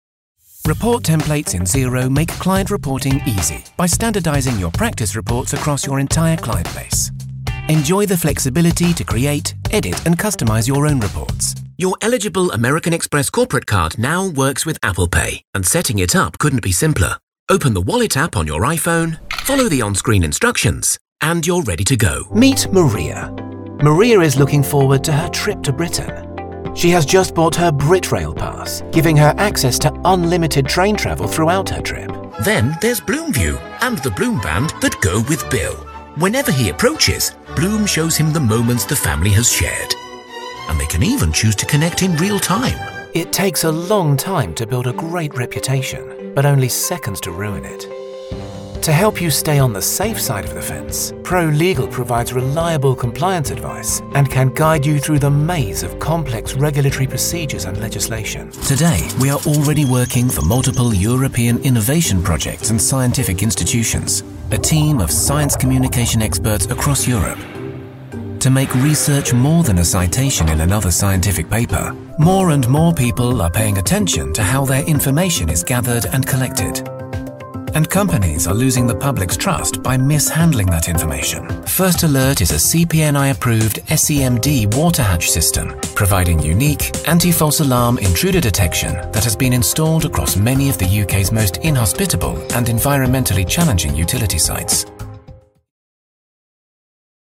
Explainer Videos
Session Booth / Neumann TLM103 / Audient iD4 / MacBook Pro / Adobe Audition
BaritoneBassDeepLow
TrustworthyAuthoritativeWarmConversationalFriendly